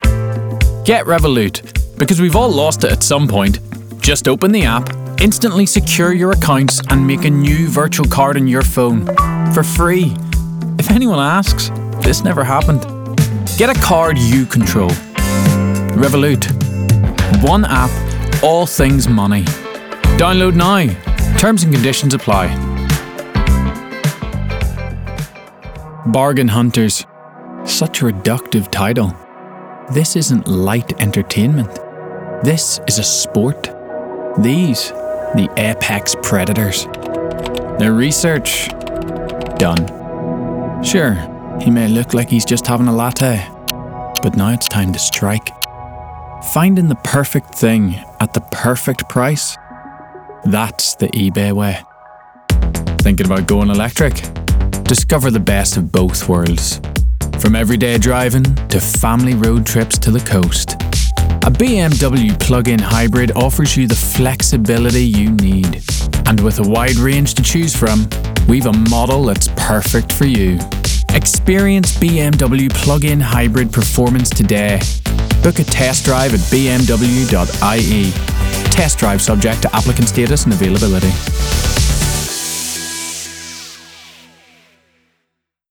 Commercial Showreel
Male
Irish
Bright
Confident
Upbeat